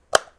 switch23.wav